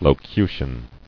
[lo·cu·tion]